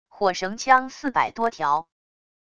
火绳枪四百多条wav音频